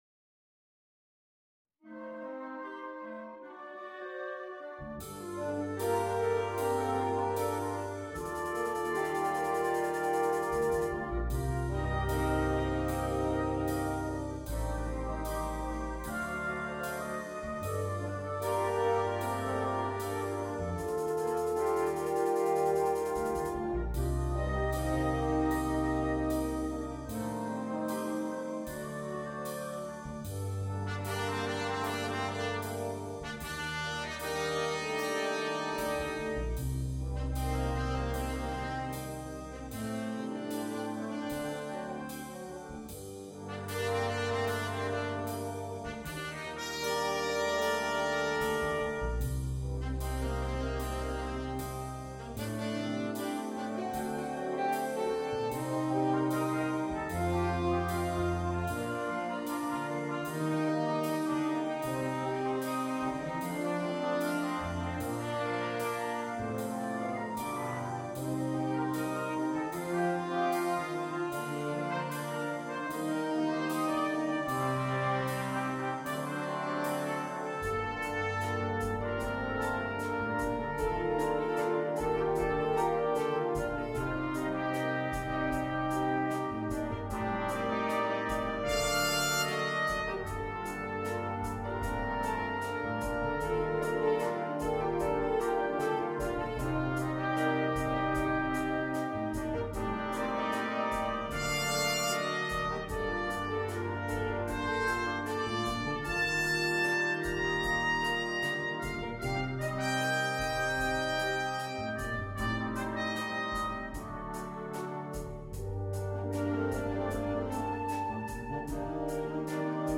на духовой оркестр